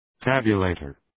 Προφορά
{‘tæbjə,leıtər}